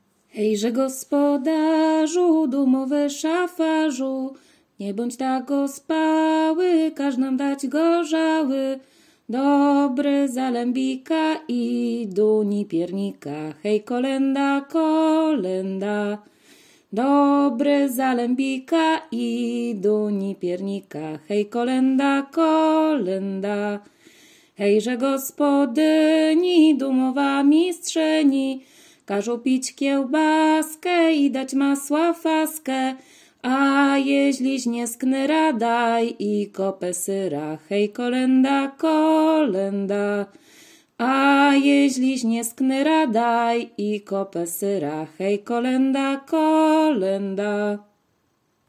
Kaliskie
Kolęda
zima kolędowanie kolędy kolędy życzące pastorałki